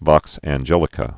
(vŏks ăn-jĕlĭ-kə)